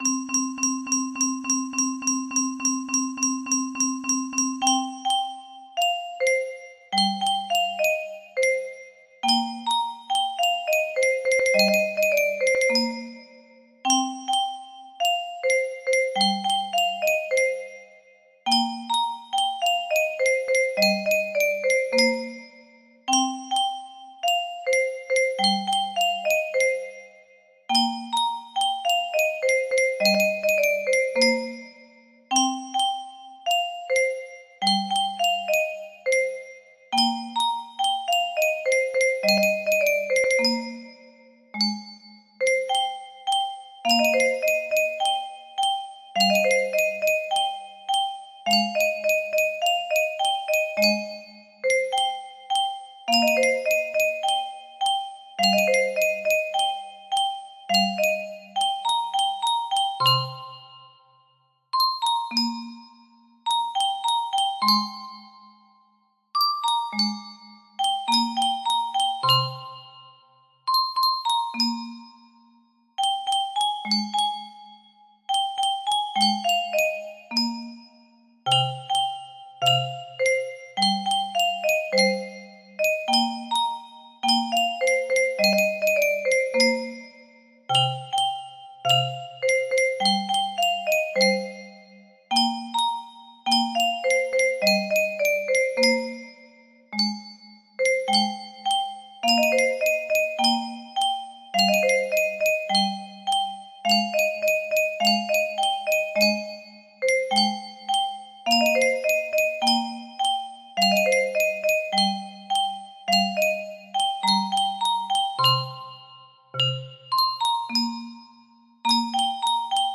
Full range 60
Imported from MIDI File